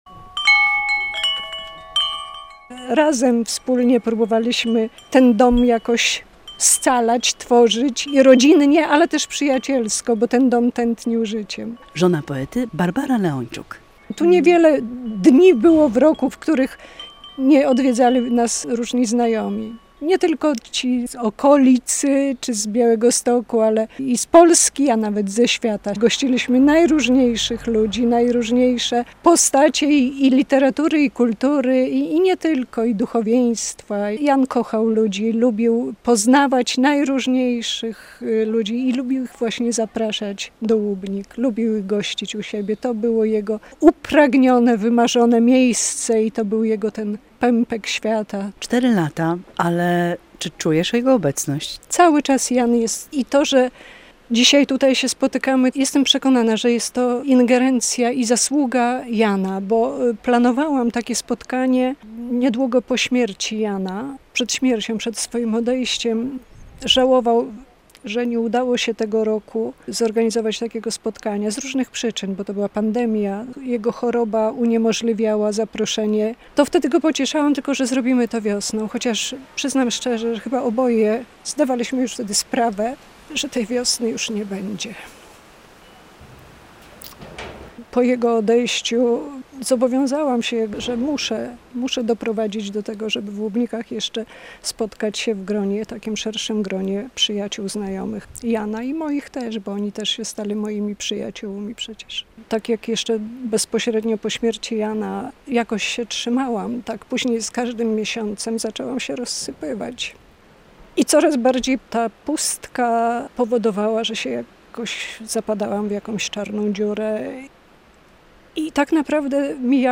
W Łubnikach spotkanie przyjaciół poety
Choć już bez obecności Jana Leończuka - przyjaciele oraz miłośnicy jego twórczości obchodzili urodziny poety w jego domu w Łubnikach.